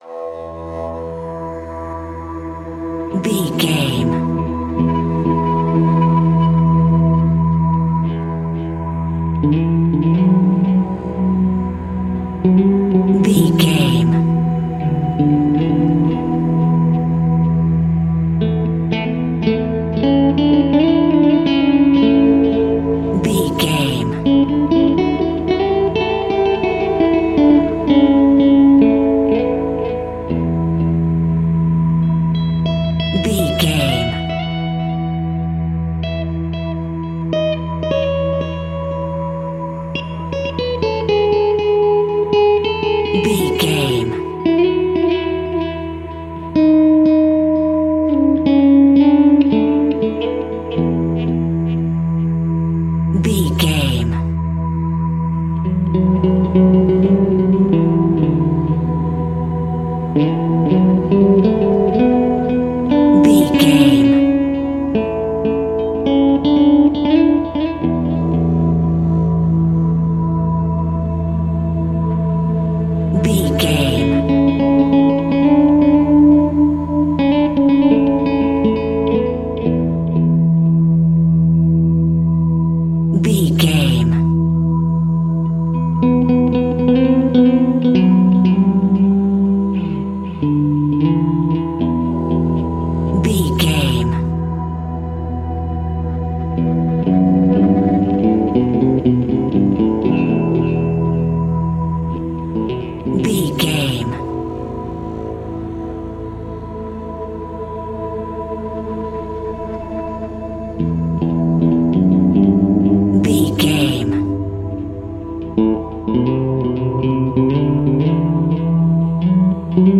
Ionian/Major
Slow
suspense
tension
World Music
acoustic guitar
bass guitar
spanish guitar